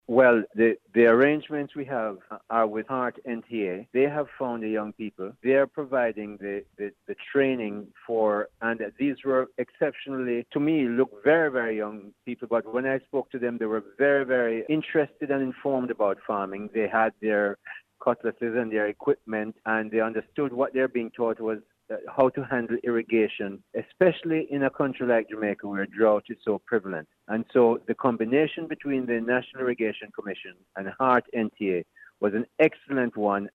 Interview with Sen. Aubyn Hill- Chairman of the Board